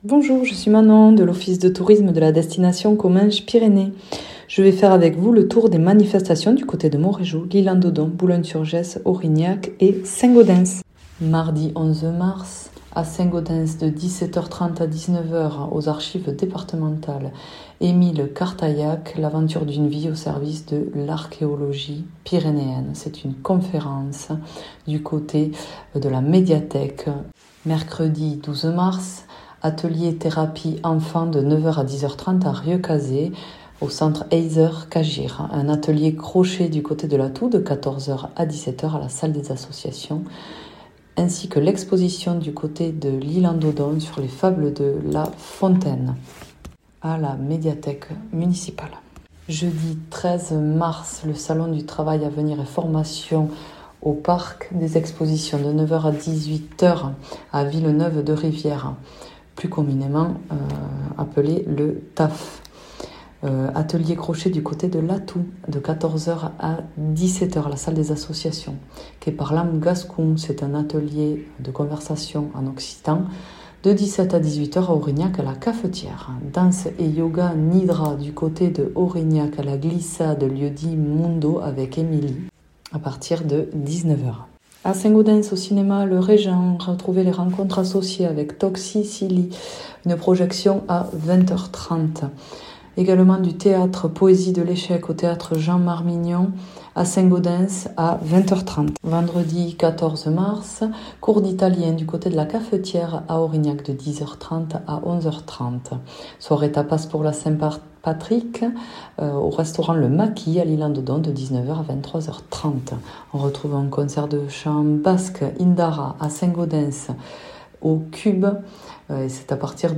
mardi 11 mars 2025 Infos, agenda en Comminges, Neste, Barousse, vallée d’Aure et du Louron Durée 5 min
Journaliste